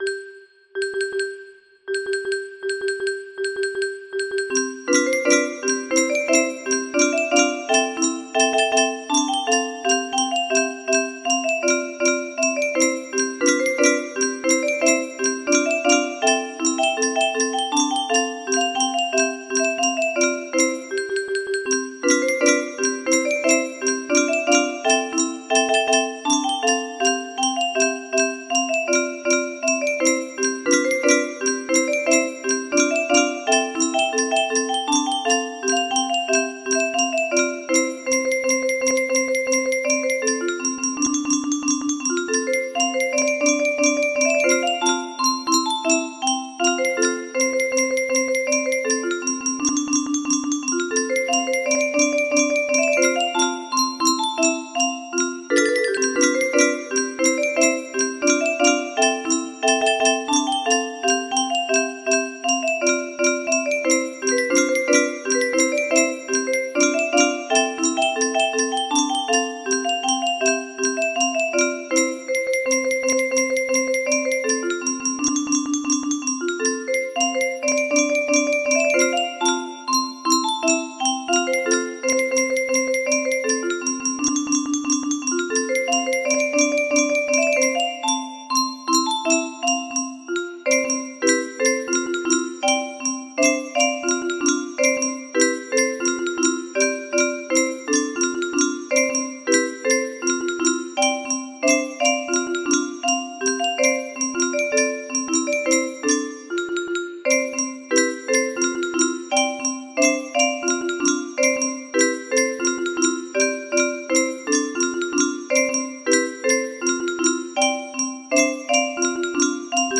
chp_op18 music box melody